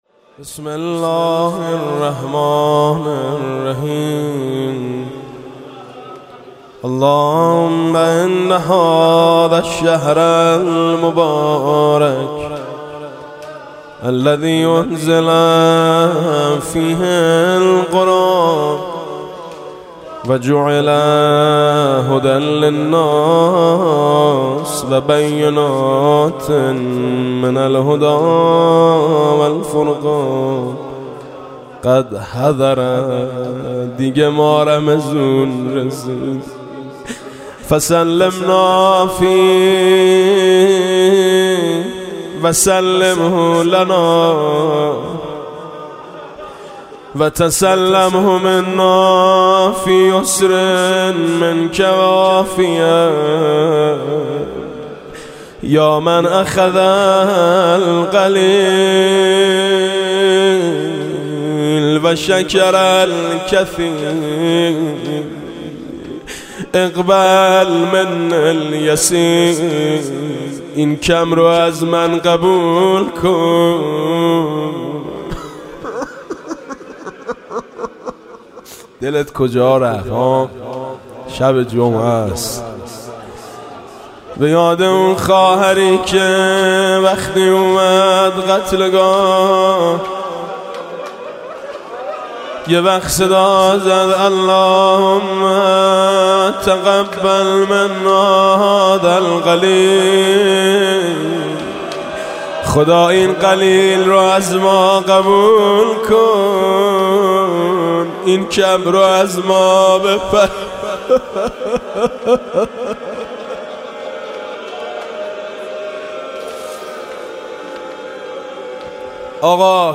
در آخرین روز از ماه شعبان و در آستانه ورود به ماه مبارک رمضان، دعای وداع با ماه شعبان را با نوای حاج میثم مطیعی می‌شنوید.